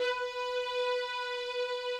strings_059.wav